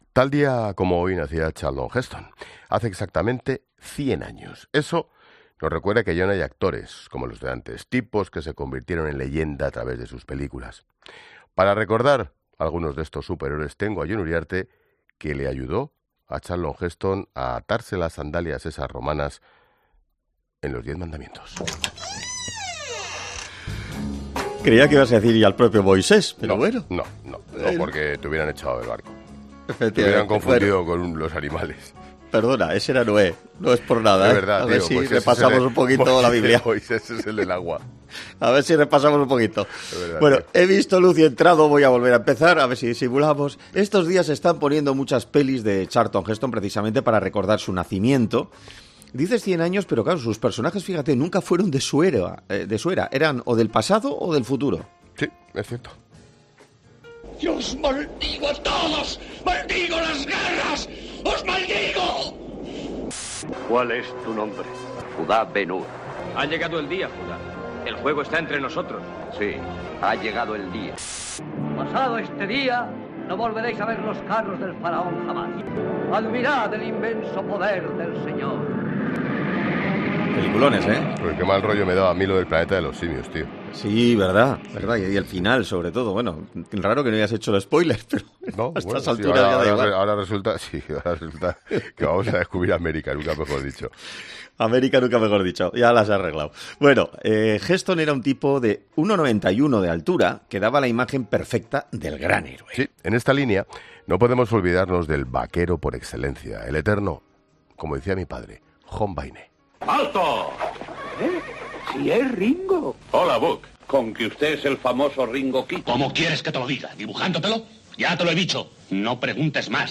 Finalizaban poniendo la canción de Lee Marvin, Wand'rin Stary